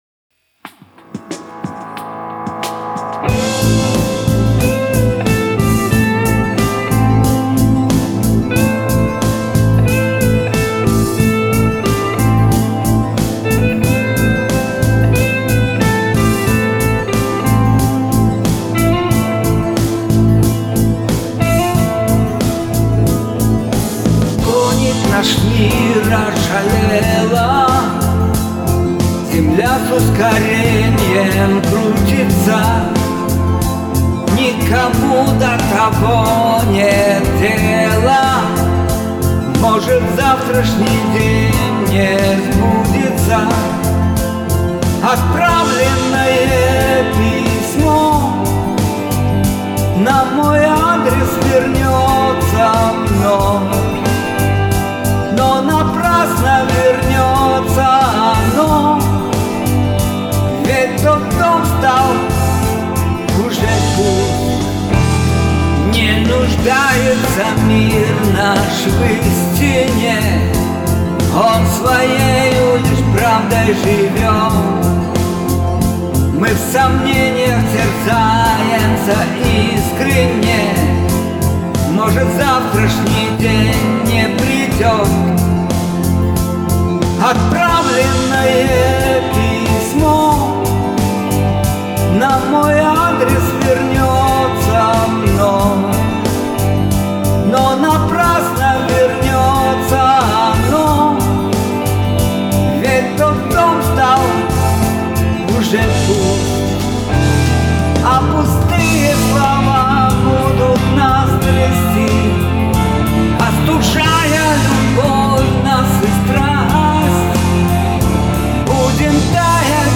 с гитарным аккомпанементом